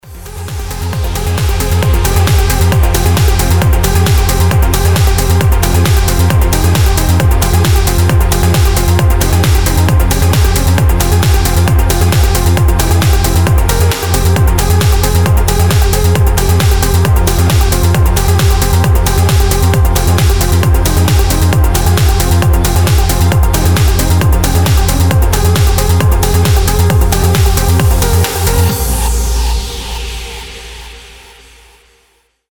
• Качество: 320, Stereo
громкие
Electronic
без слов
быстрые
транс
Классная транс музыка